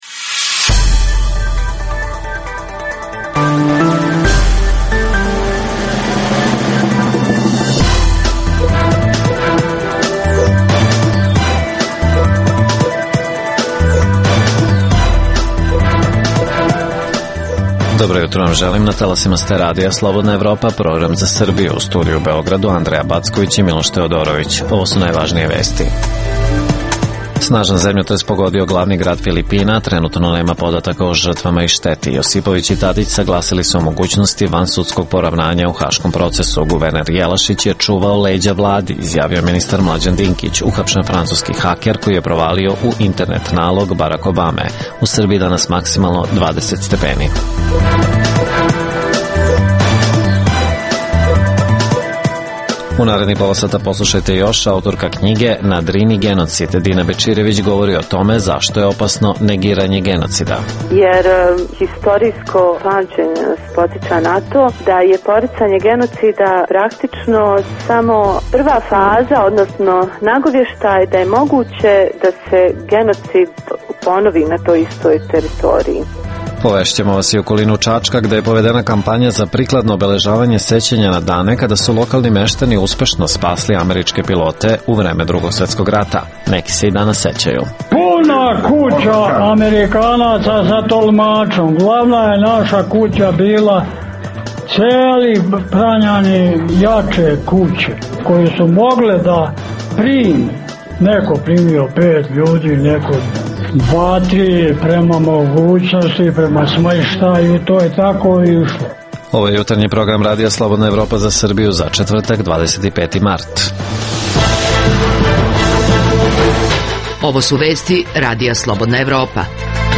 - Prenosimo i reportažu iz jednog sela nadomak Čačka gde meštani traže da se na adekvatan način obeleži sećanje na vreme kada su lokalni seljaci pomogli izgradnju improvizovanog aerodroma i spasili američke pilote.